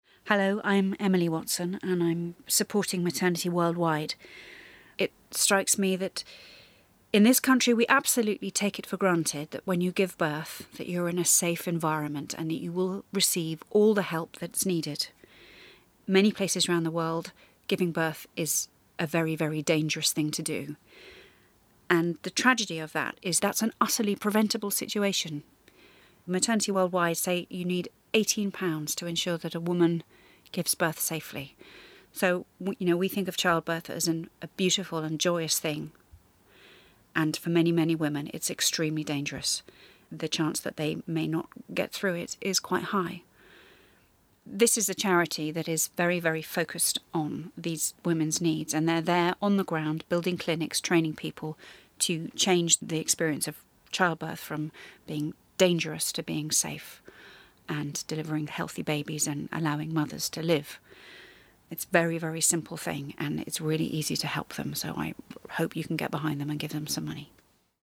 At the end of April, English actress, Emily Watson shared our appeal message with you and told you all about the vital work Maternity Worldwide are doing to ensure more lives will be saved in pregnancy and childbirth in Africa.